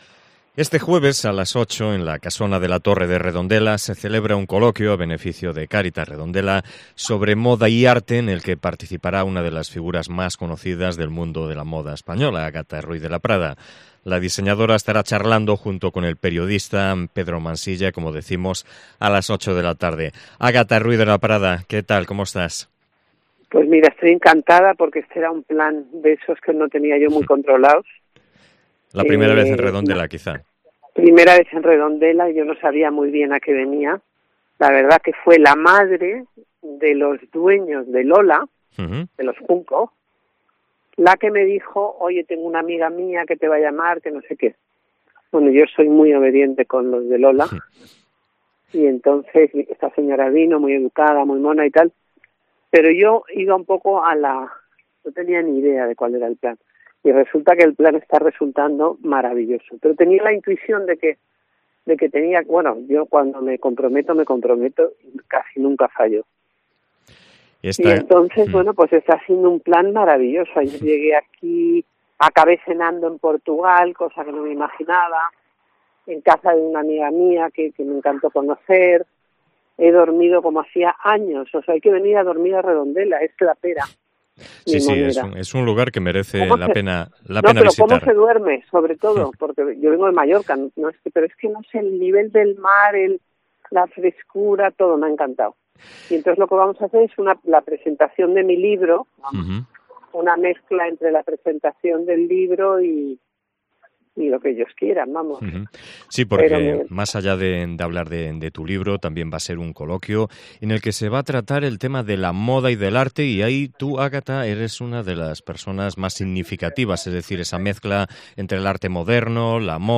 Entrevista a Ágatha Ruiz de la Prada en COPE Vigo